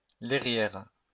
Lairière (French pronunciation: [lɛʁjɛʁ]